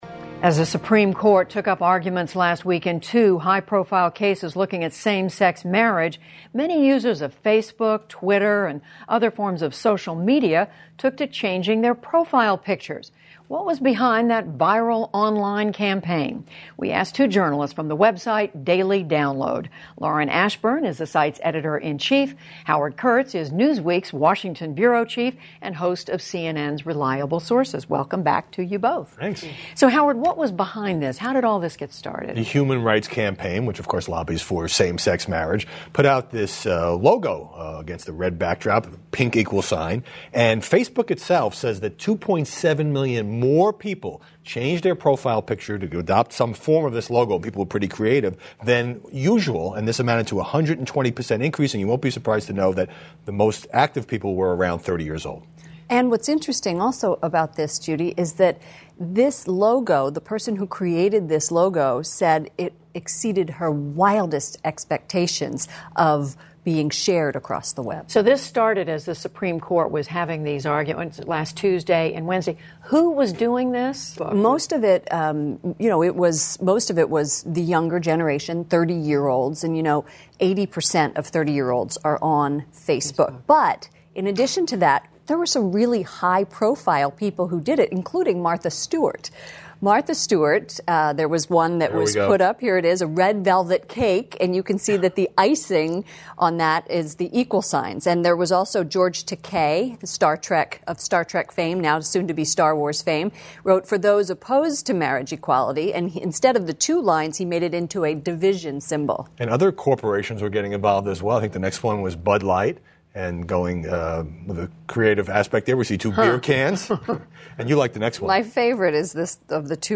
英语访谈节目:同性恋已经"占领"Facebook了?